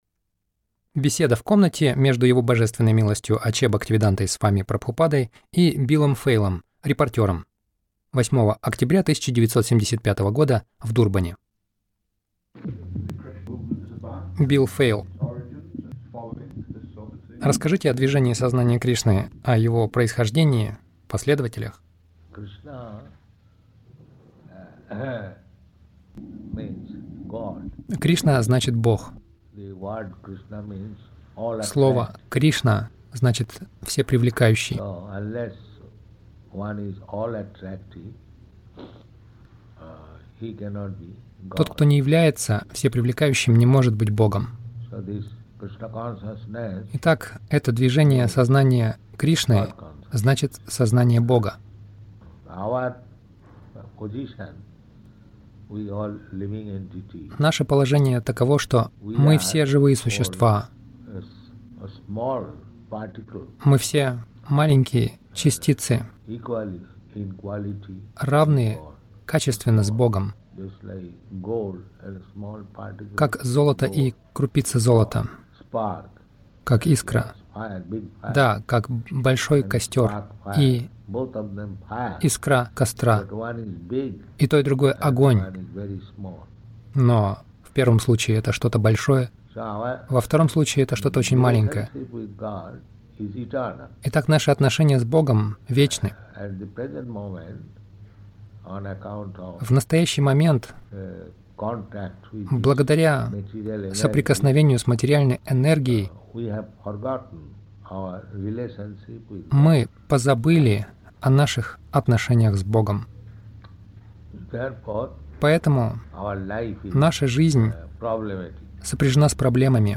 Интервью — О Сознании Кришны
Милость Прабхупады Аудиолекции и книги 08.10.1975 Интервью | Дурбан Интервью — О Сознании Кришны Загрузка...